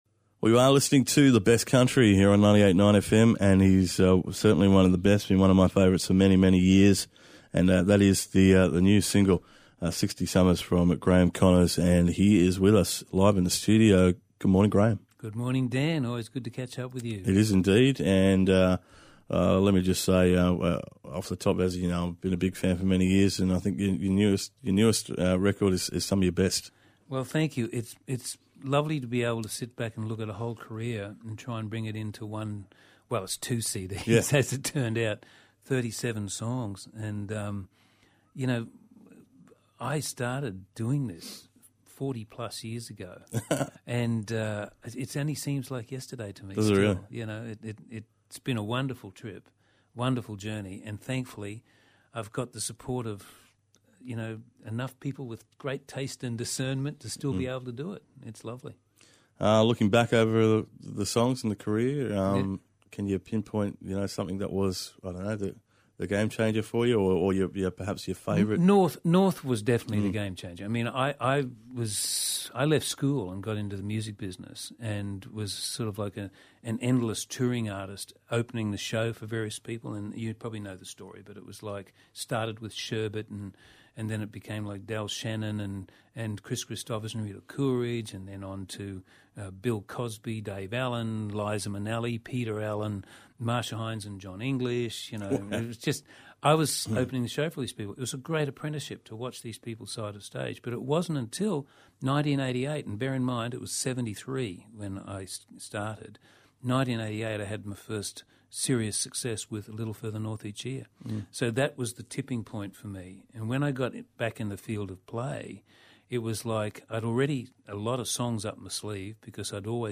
GraemeConnorsInterview2016-1.mp3